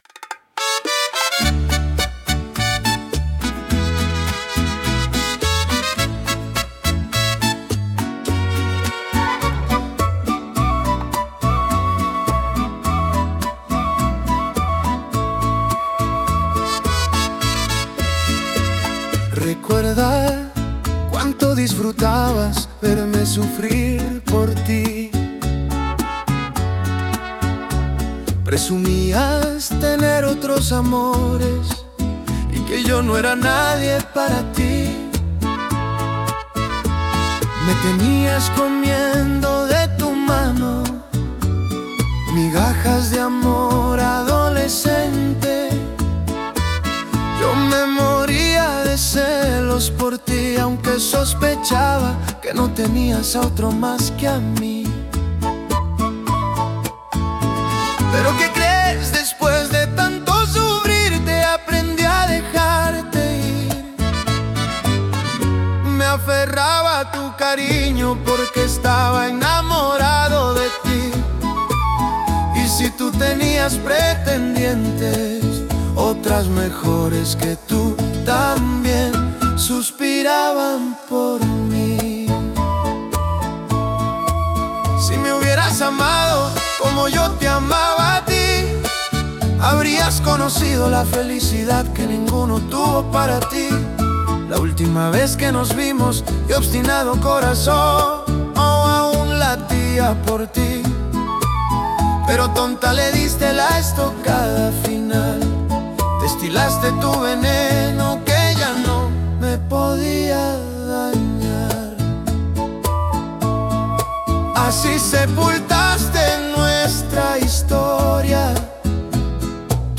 Genre Mariachi